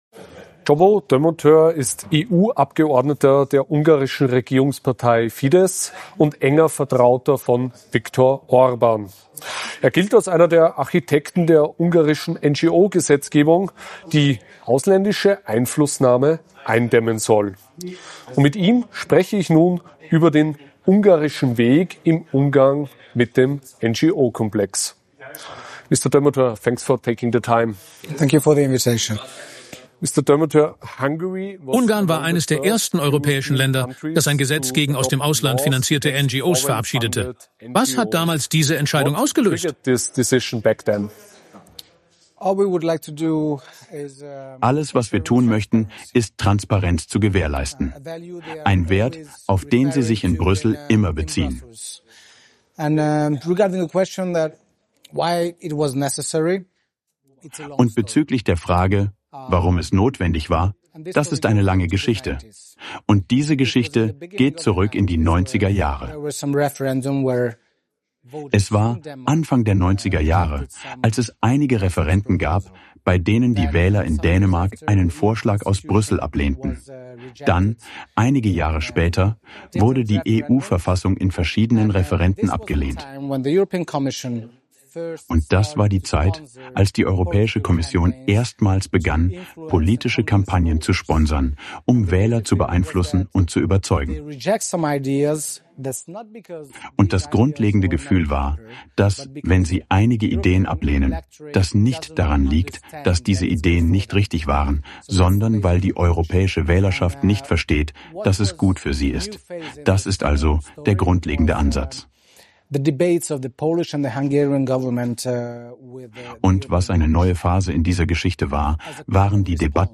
Der ungarische EU-Abgeordnete und Orbán-Vertraute Csaba Dömötör warnt im AUF1-Interview vor einer massiven Ausweitung der EU-Förderungen für linke NGOs. Trotz wachsender Kritik und laufender Strafanzeigen gegen die Kommission sollen die Geldflüsse künftig verdoppelt werden.